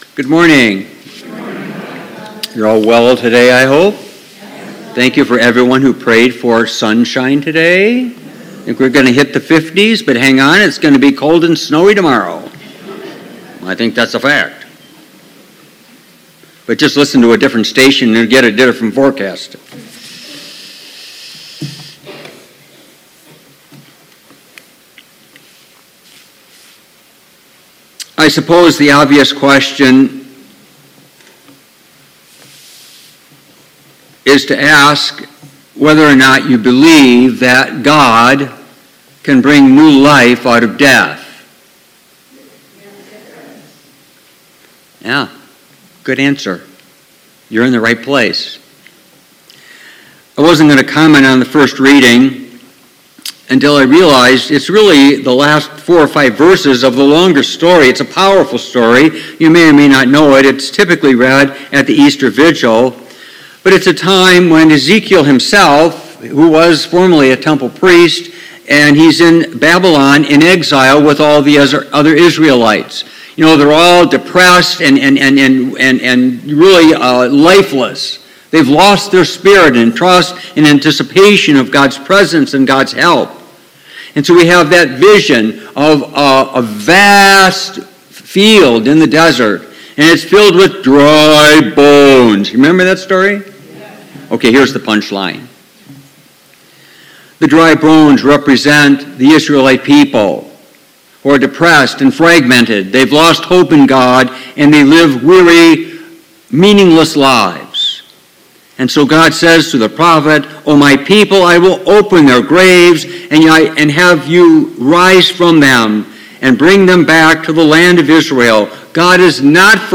Homily, 5th Sunday of Lent, March 26, 2023
Homily-5th-SOL23.mp3